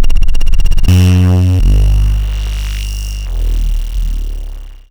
Cwejman S1 - Fuzzy Bone Saw.wav